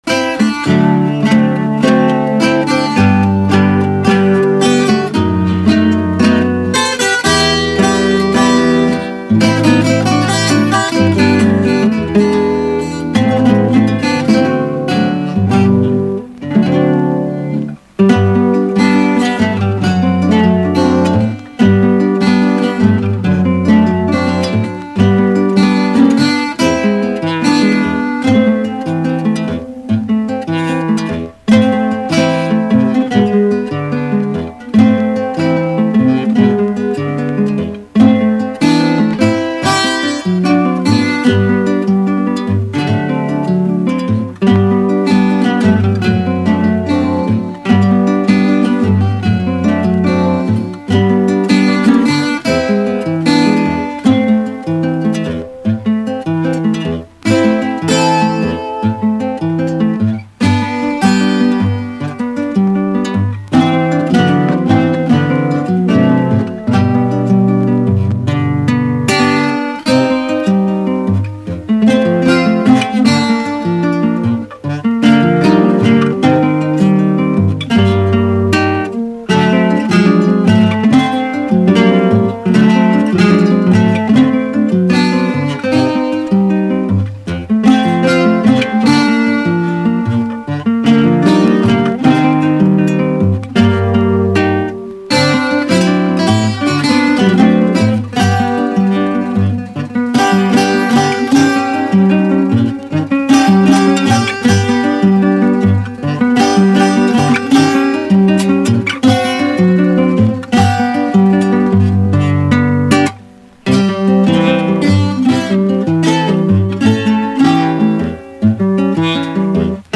Cuatro y Guitarra